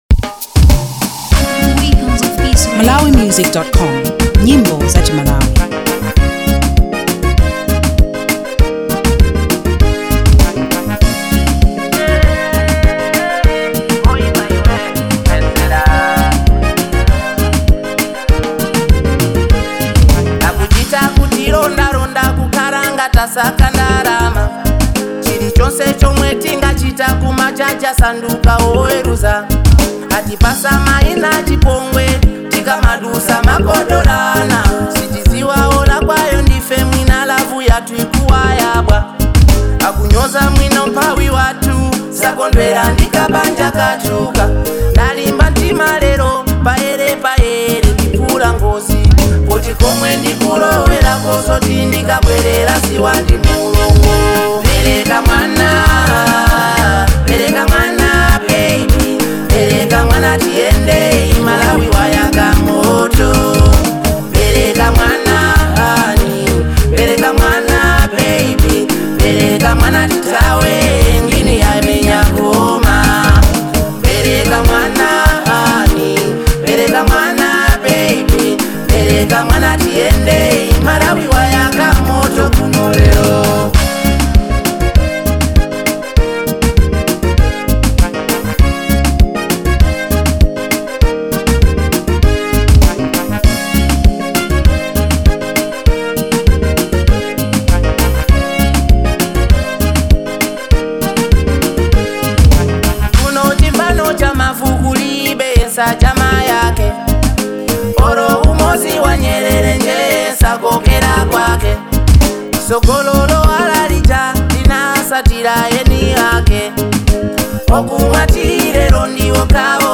Malawian Afro • 2025-07-18